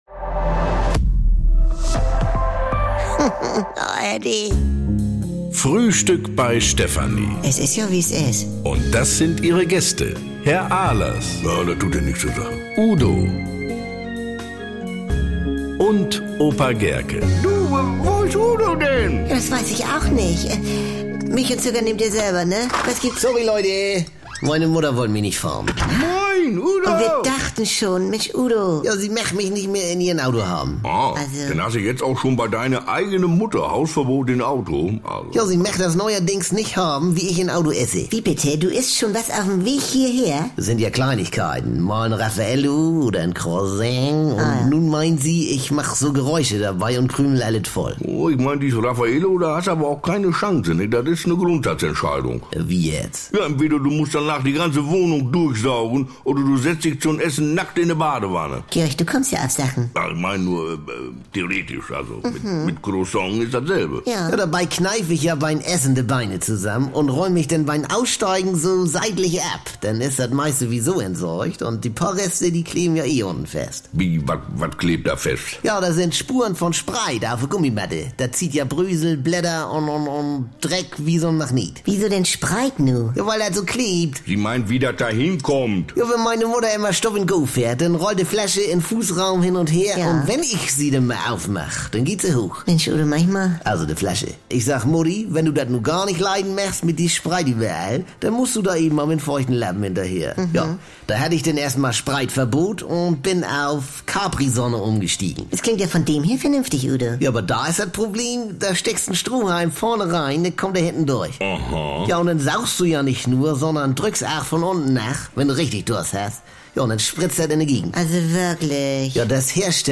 NDR 2 Komödie Frühstück Bei Stefanie